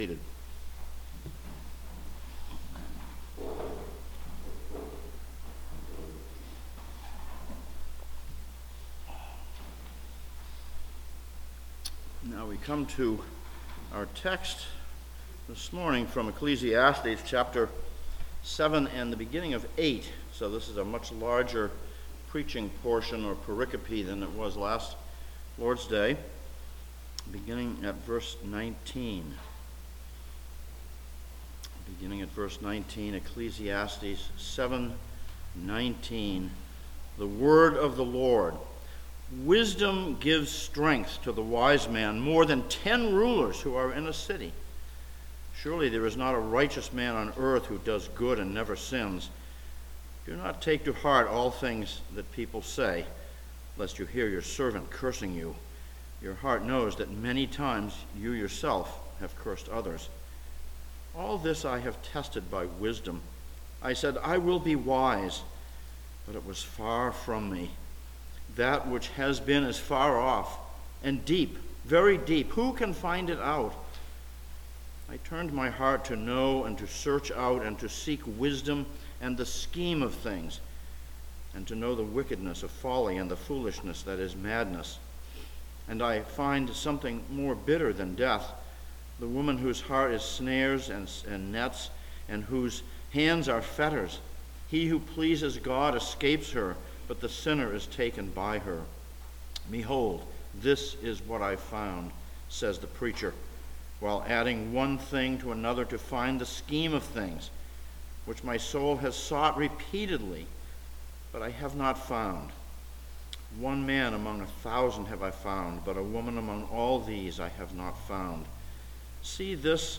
A Sermon from Ecclesiastes 7:18-8:8
Service Type: Sunday Morning